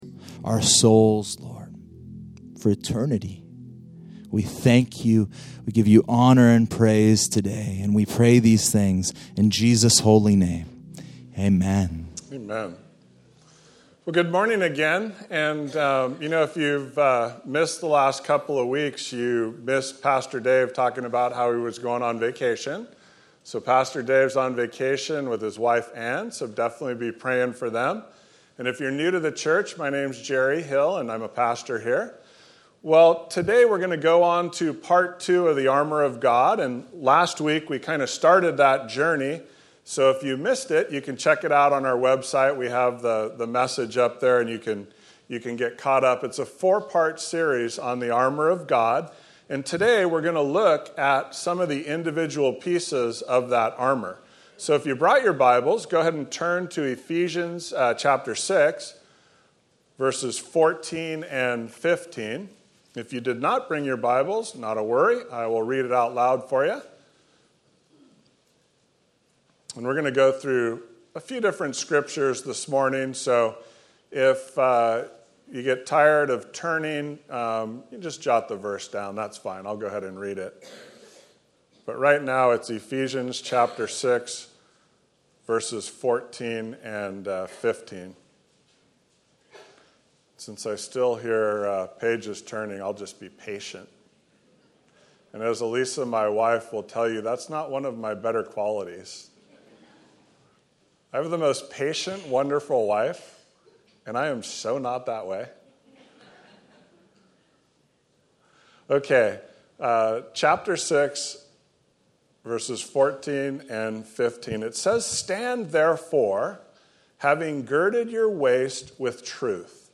Messages | Pacific Hills Calvary Chapel | Orange County | Local Church